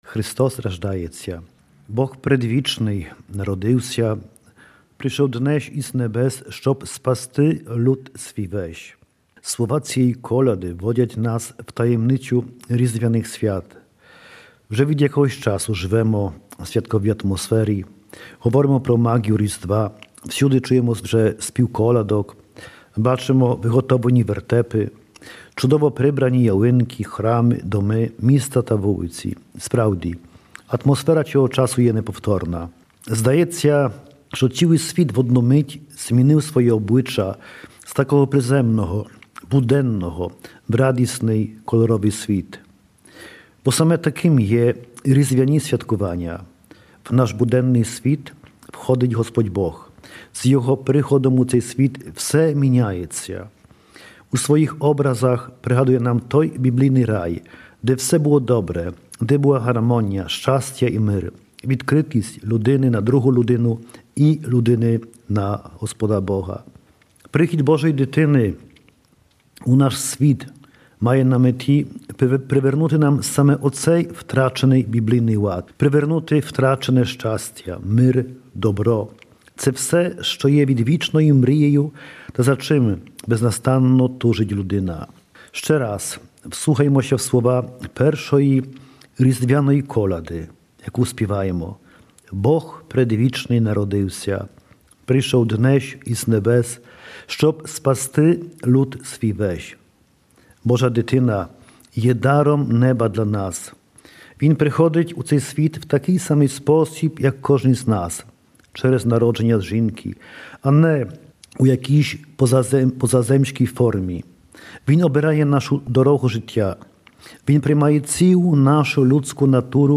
24 грудня засядемо до святої вечері. Вже другий рік поспіль святкуємо з католиками ці свята З посланням до вірних звертається архиєпископ перемишльсько- варшавської єпархії владика Євгеній Попович.